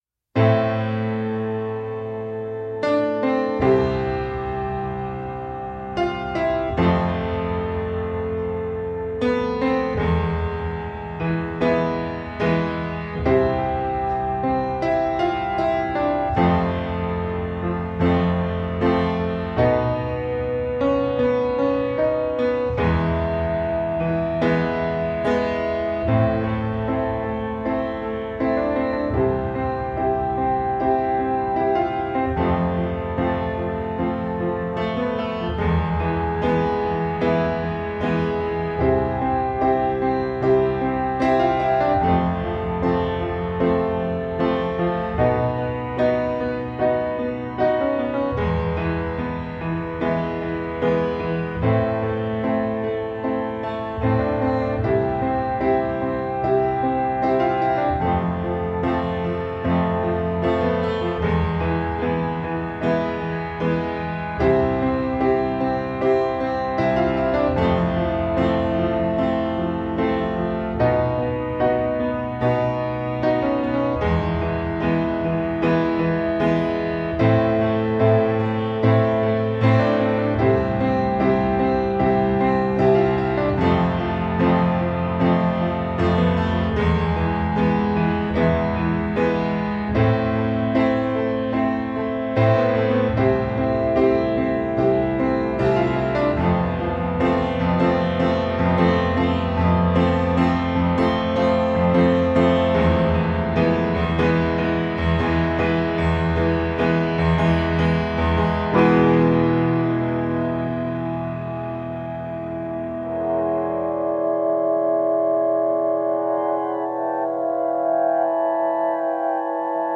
An arty thump of melody and bombast.